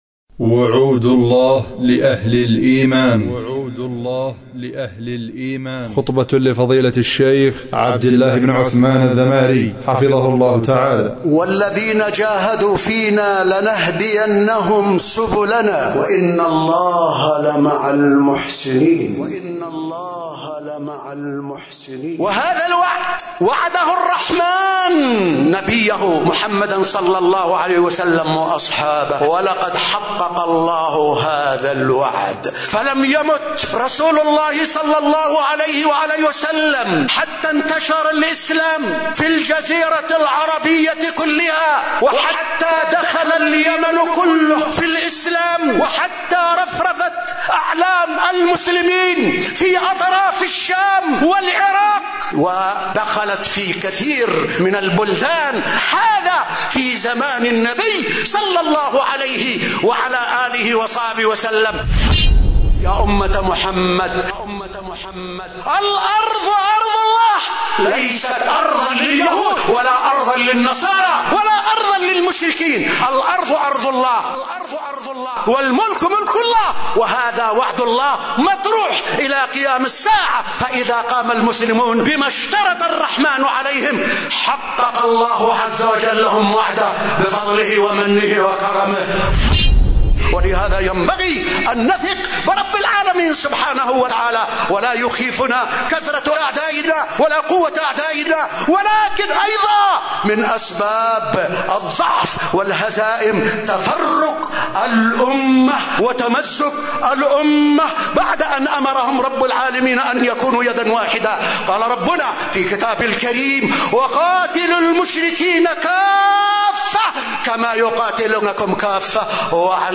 *💥 وعود الله لأهل الإيمان * *🔈خطبة الجمعة من دار الحديث بمسجد الصديق_بمدينة ذمار_اليمن*